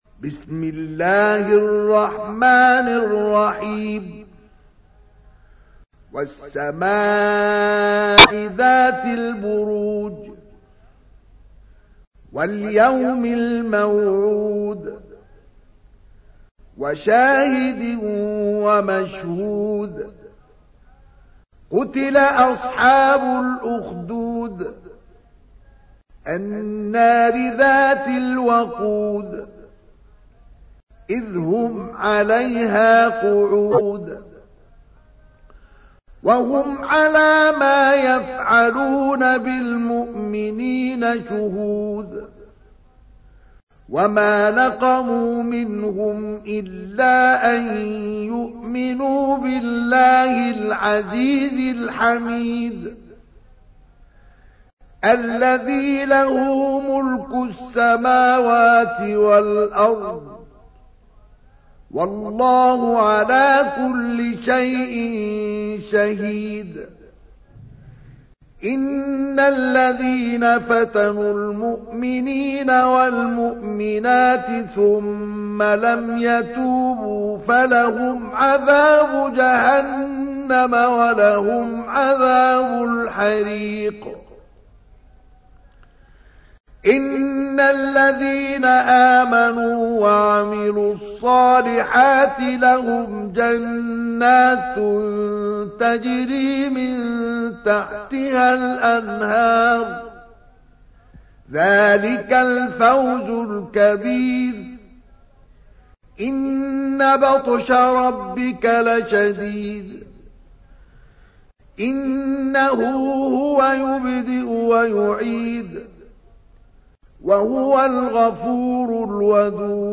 تحميل : 85. سورة البروج / القارئ مصطفى اسماعيل / القرآن الكريم / موقع يا حسين